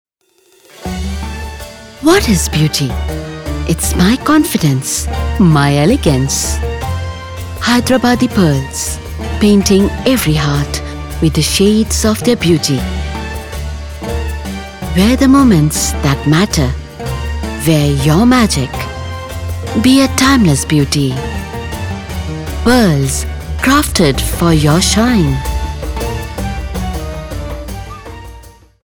Inglês (Indiano)
Conversacional
Amigáveis
Confiável